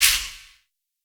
MZ FX [Drill Impact].wav